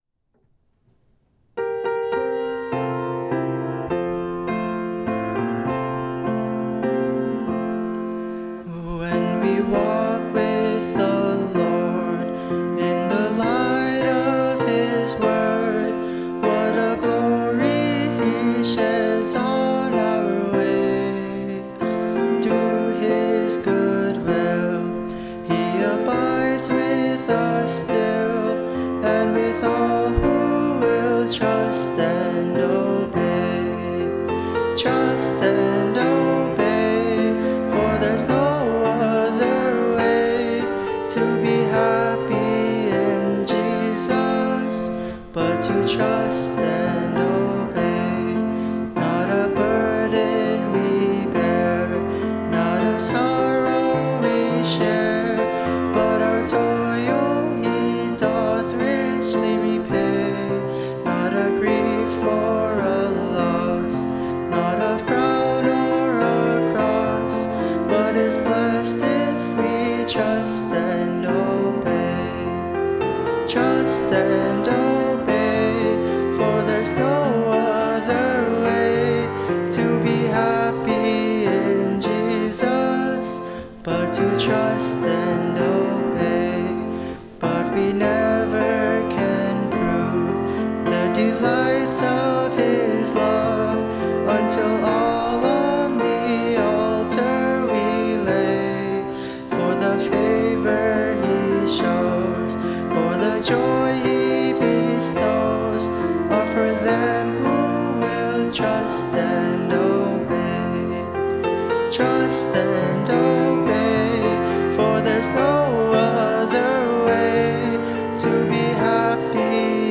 Service of Worship